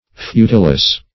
Futilous \Fu"til*ous\, a. Futile; trifling.